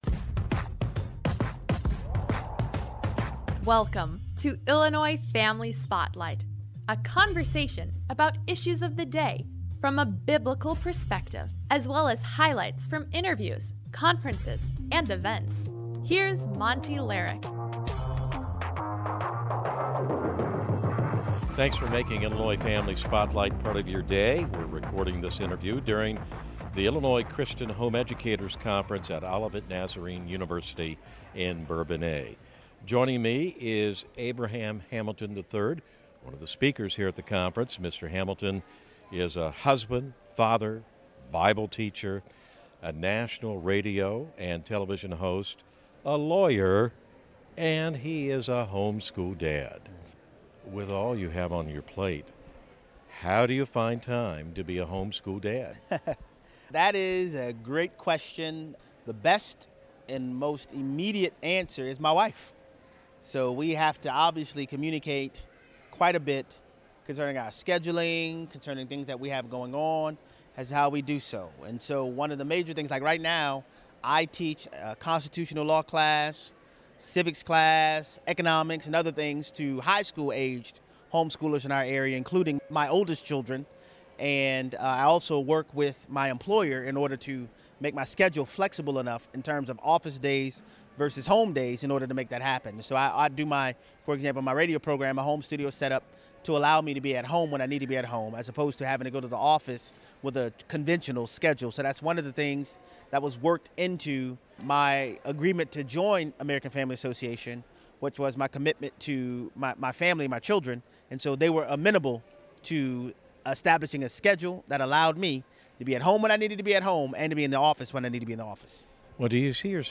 Recorded live from the 2025 Illinois Christian Homeschool Convention (ICHE)